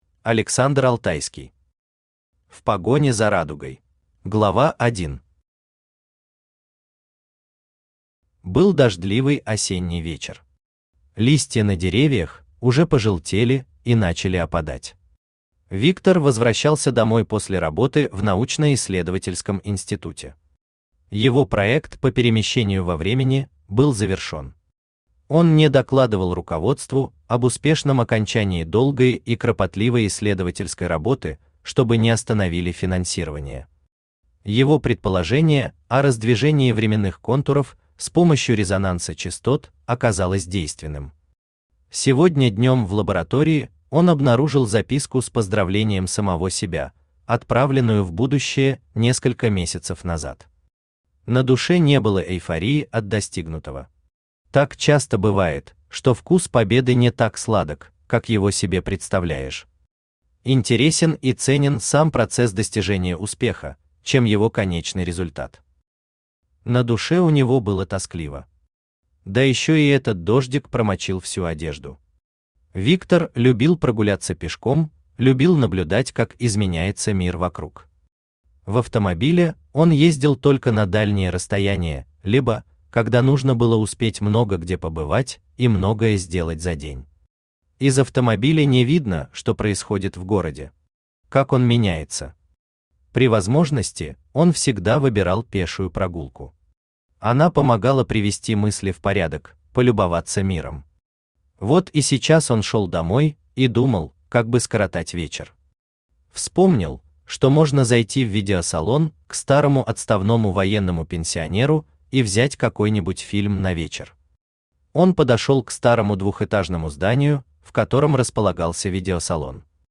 Аудиокнига В погоне за радугой!
Автор Александр Алтайский Читает аудиокнигу Авточтец ЛитРес.